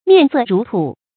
面色如土 注音： ㄇㄧㄢˋ ㄙㄜˋ ㄖㄨˊ ㄊㄨˇ 讀音讀法： 意思解釋： 見「面如土色」。